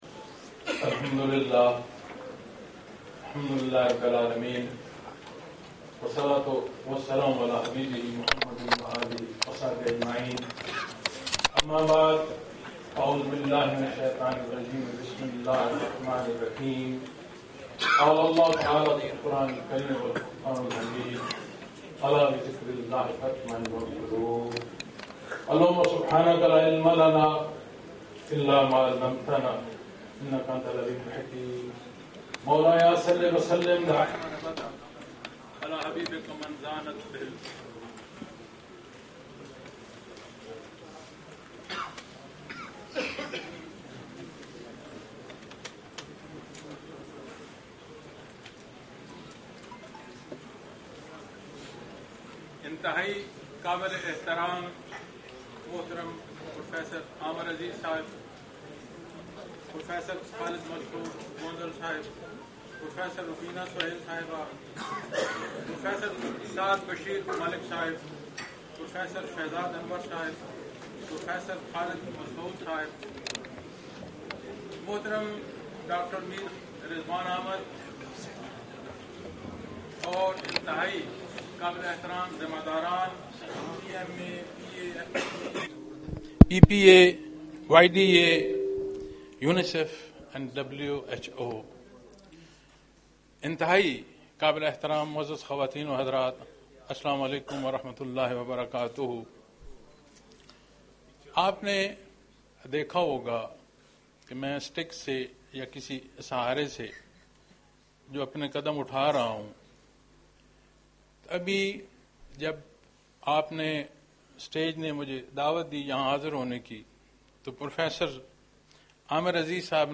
آل پاکستان کانفرنس الفلاح فاؤنڈیشن پاکستان لاہور